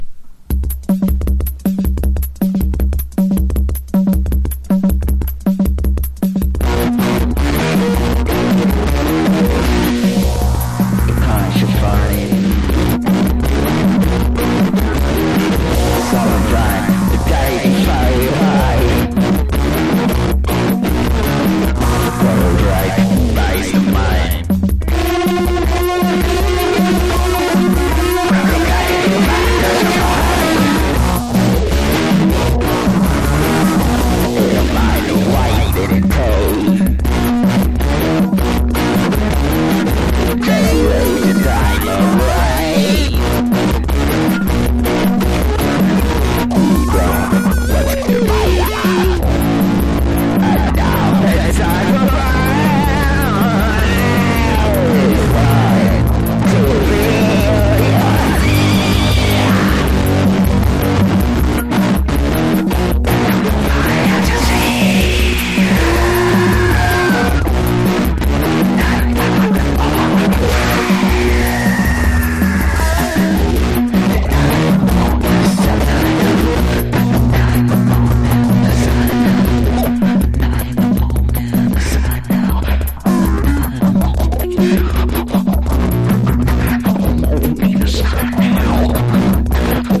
1. 90'S ROCK >
# ALTERNATIVE / GRUNGE